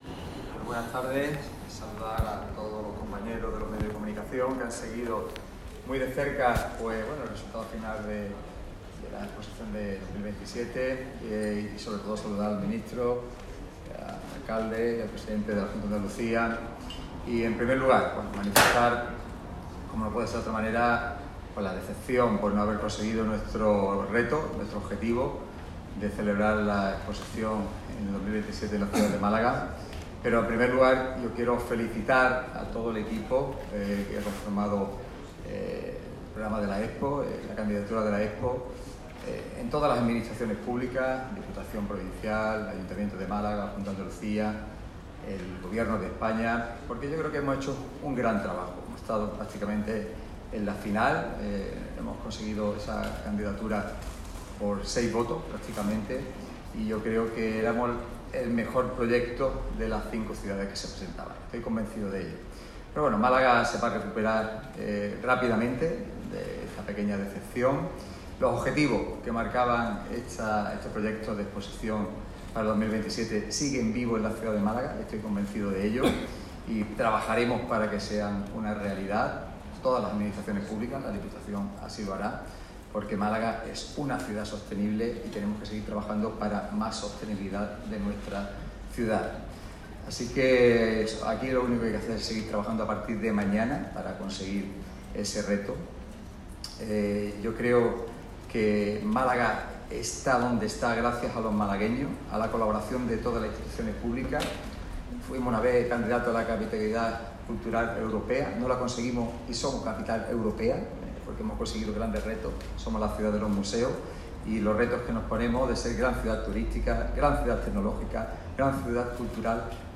En rueda de prensa conjunta, el alcalde de Málaga, Francisco de la Torre; el presidente de la Diputación en funciones, Francisco Salado; el presidente de la Junta de Andalucía, Juanma Moreno, y el ministro de Asuntos Exteriores, Unión Europea y Cooperación, José Manuel Albares, han incidido en esta idea y han trasladado el agradecimiento por el trabajo desarrollado durante todo este tiempo.
Rueda de prensa con las administraciones tras la Asamblea del BIE